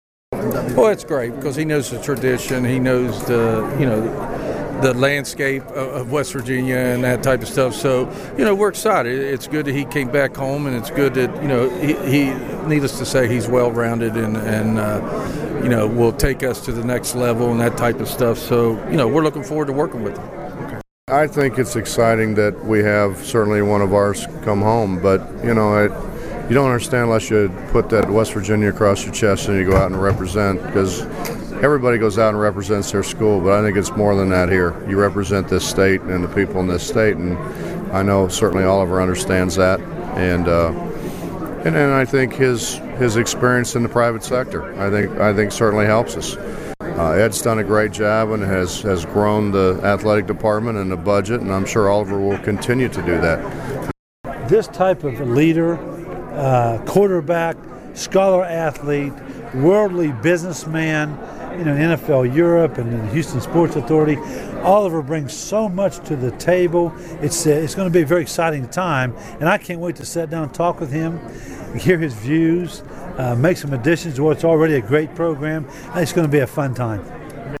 Click below to hear Women's Basketball Coach Mike Carey, Men's Basketball Coach Bob Huggins and Football Coach Bill Stewart on the hiring of Oliver Luck as athletic Director: